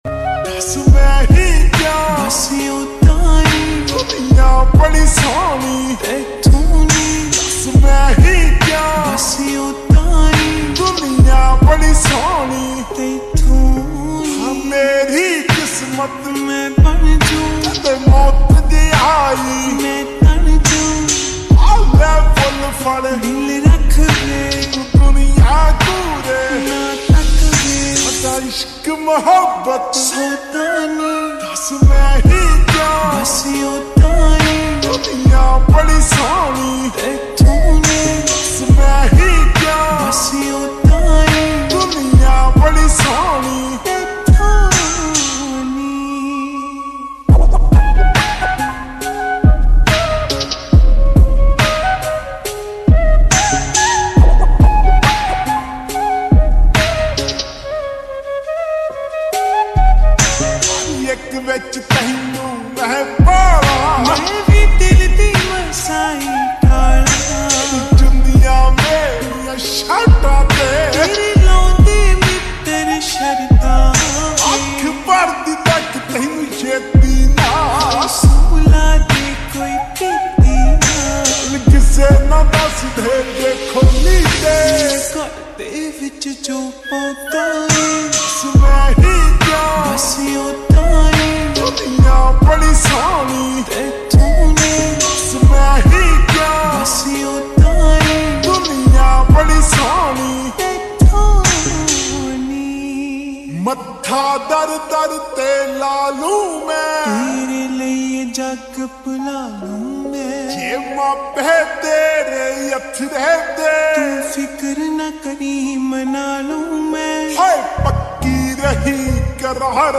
slowed x reverb